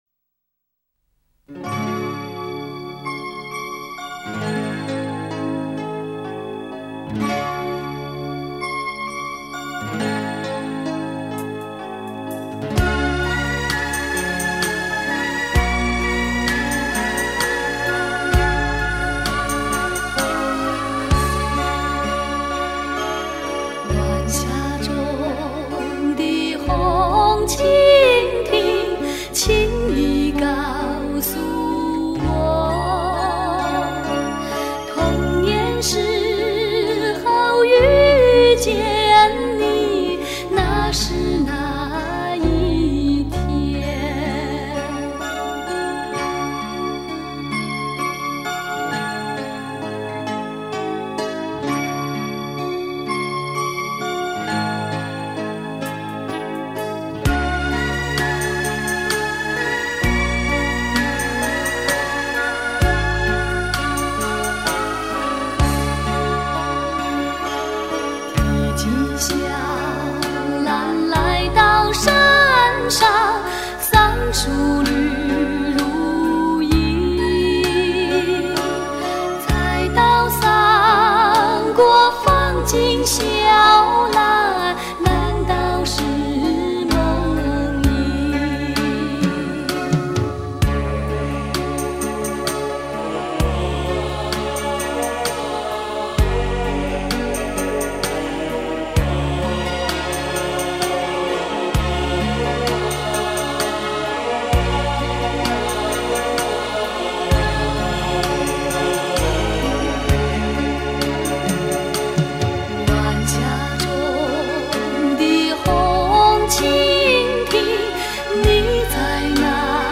采用3/4拍、宫调式，全曲只有8小节，为上、下乐句构成的一段体结构。歌曲的主旋律起伏较大，音域从“ - ”达十度，和声式的第二声部围绕主音“1”起伏不大，使歌曲的和声色彩非常浓厚。